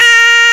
Index of /m8-backup/M8/Samples/Fairlight CMI/IIX/CHORAL
AHH1.WAV